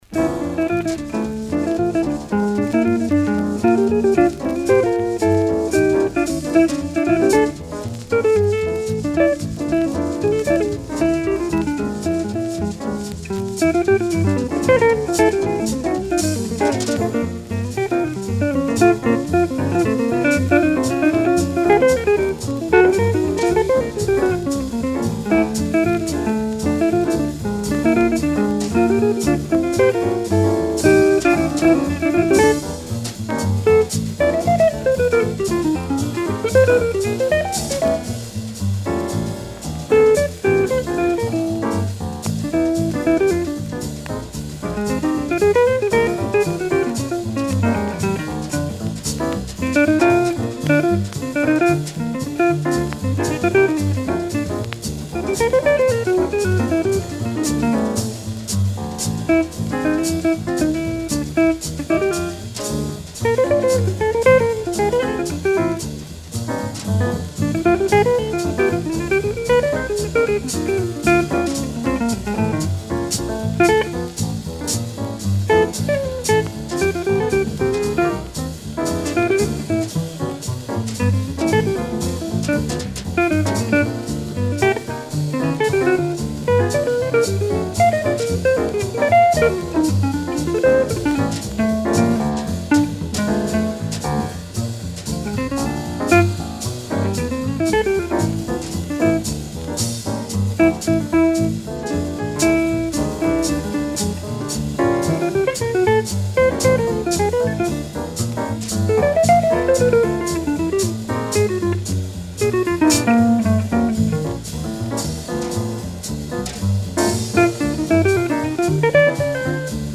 Original 1954 mono pressing